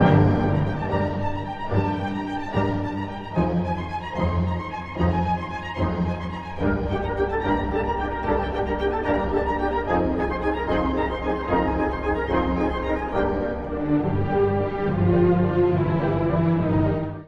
古い音源なので聴きづらいかもしれません！（以下同様）